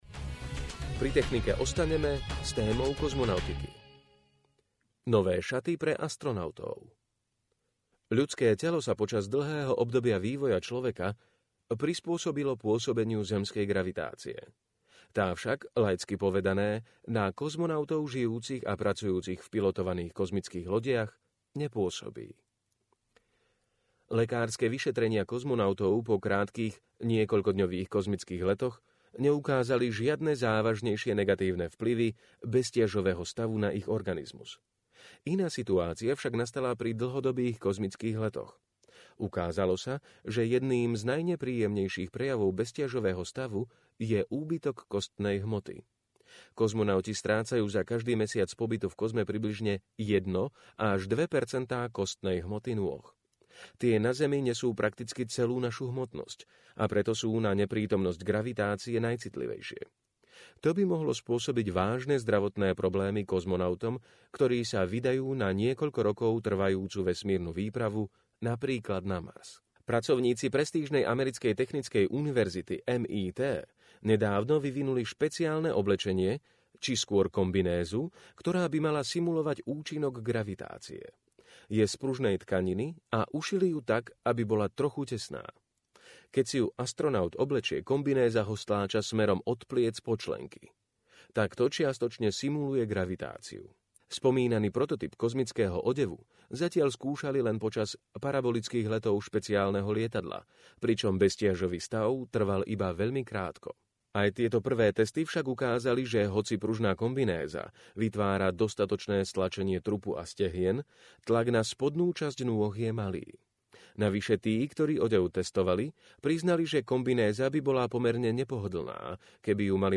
Opäť vás tu čaká obľúbené hodinové čítanie z nového Quarku, aby si mohli vaše oči oddýchnuť.